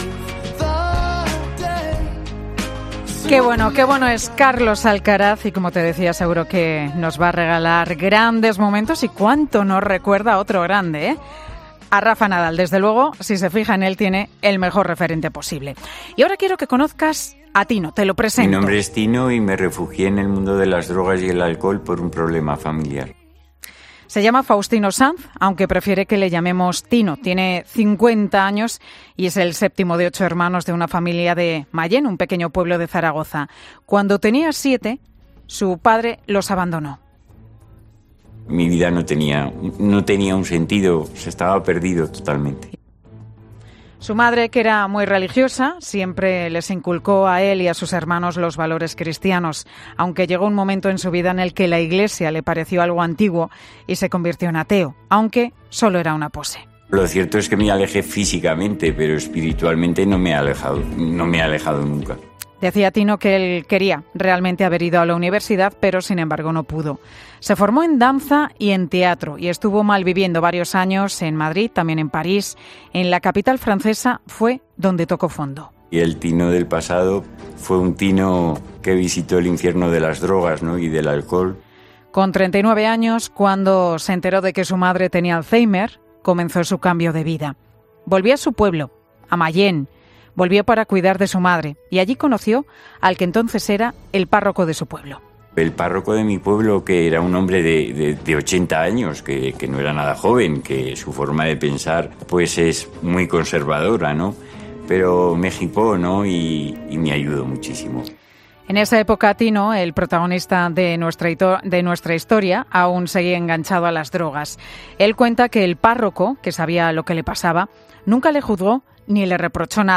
"En realidad, la Iglesia haría esta labor igualmente aunque no hubiese casilla porque ayudar a la gente es parte de su razón de ser", ha reconocido monseñor Joseba Segura en el programa que presenta Pilar García Muñiz.